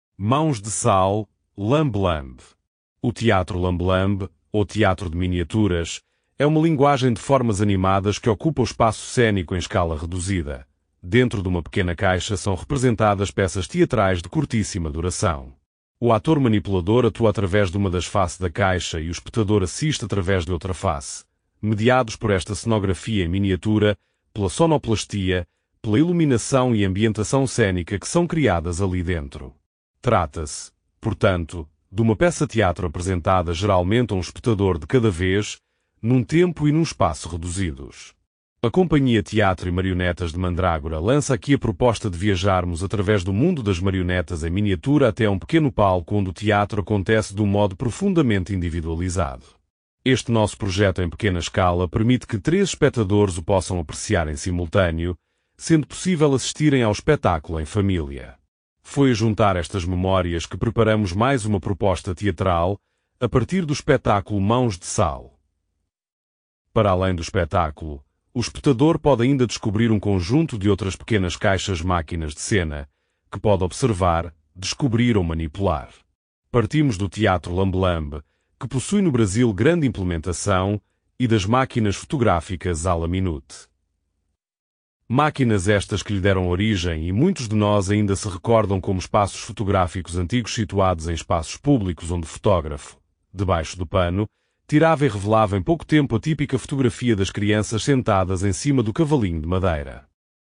este áudio guia possui 13 faixas e duração de 00:19:43, num total de 13.9 Mb